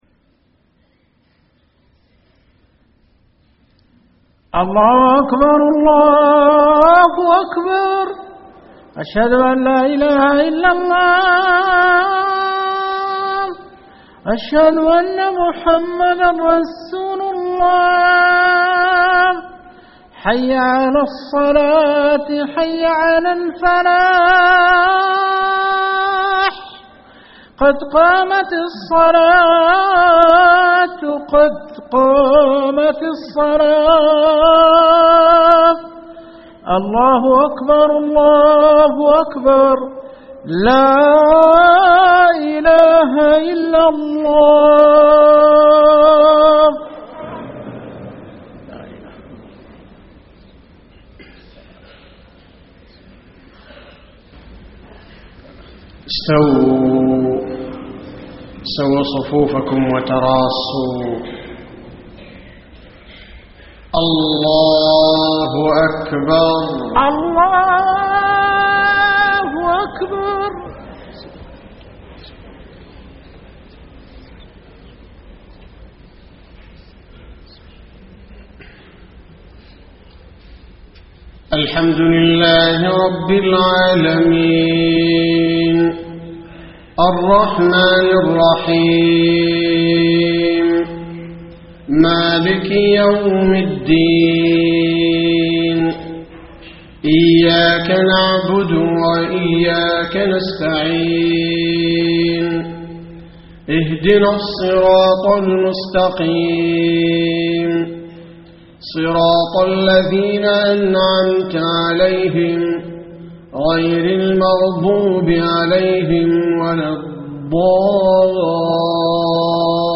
صلاة الفجر 7-6-1434 من سورة البقرة > 1434 🕌 > الفروض - تلاوات الحرمين